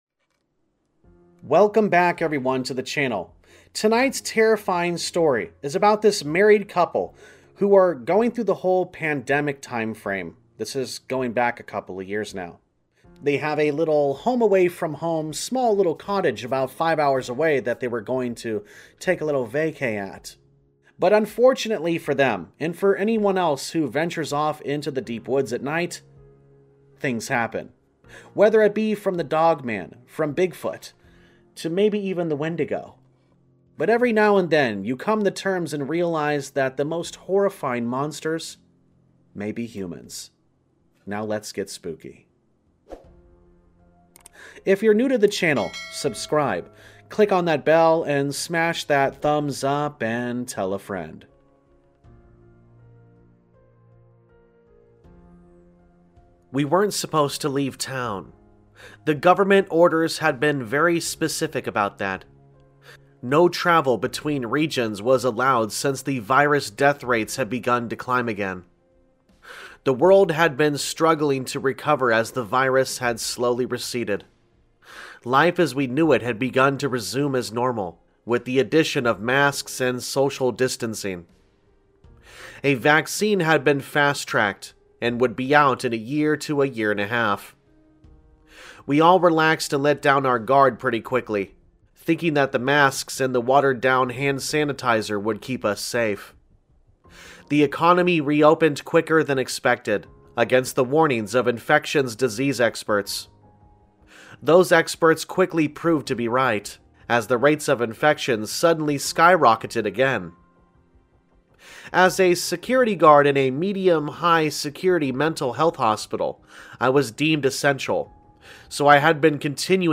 Campfire Tales is a channel which is focused on Allegedly True Scary Stories and Creepypastas.